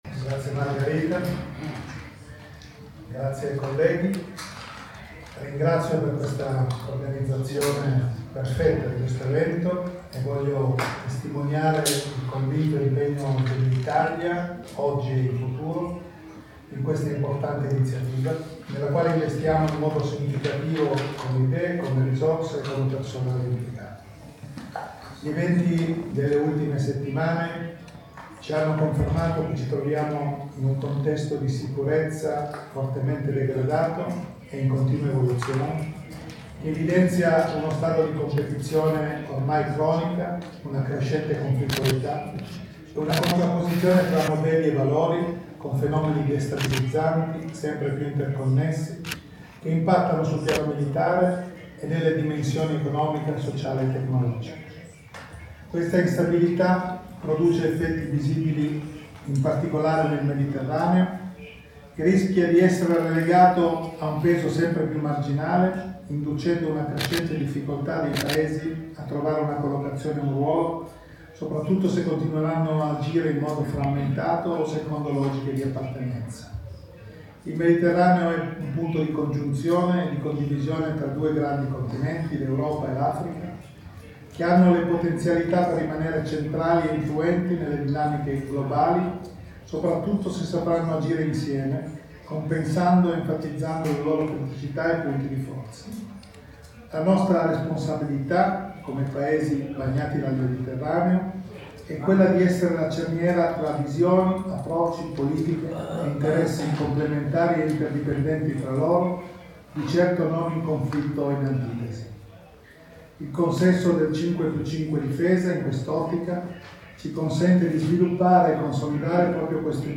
Intervista del Ministro Crosetto a Madrid